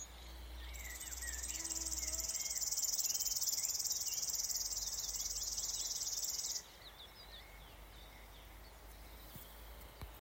Птицы -> Славковые ->
речной сверчок, Locustella fluviatilis
Administratīvā teritorijaAlūksnes novads
СтатусПоёт